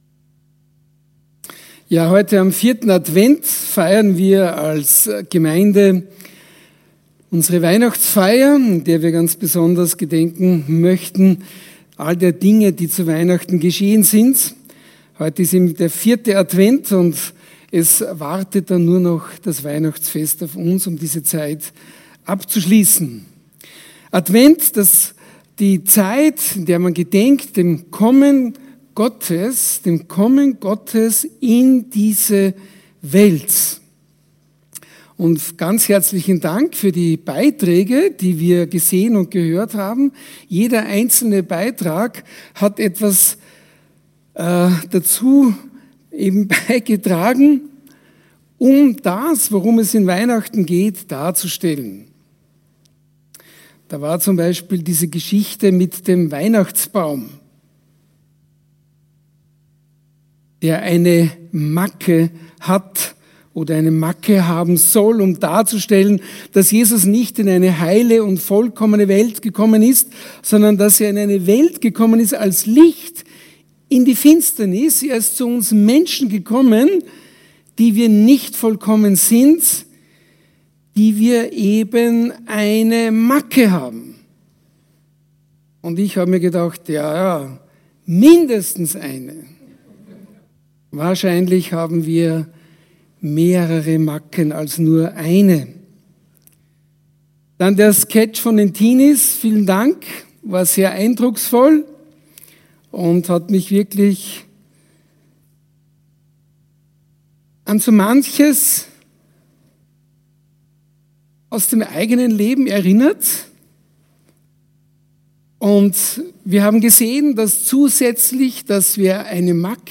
Predigten
Hier finden Sie die aktuellen Sonntagspredigten der Baptistengemeinde Basel.